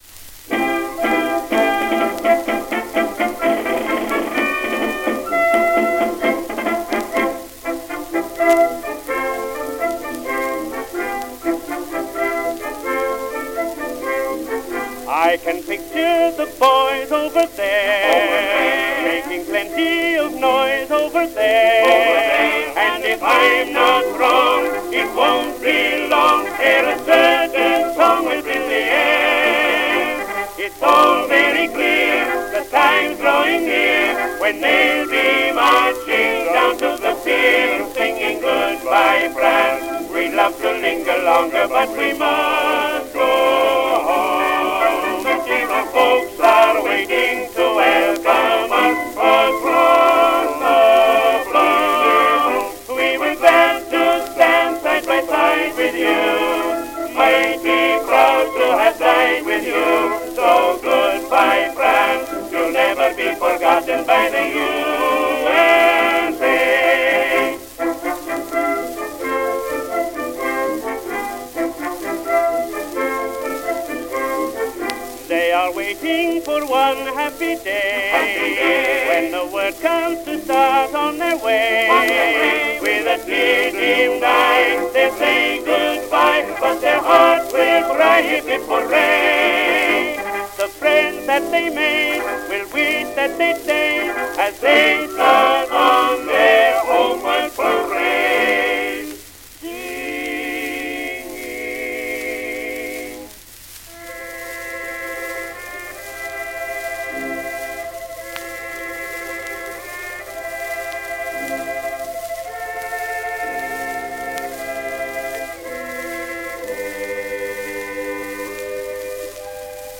Tenor Vocal
Vocal Group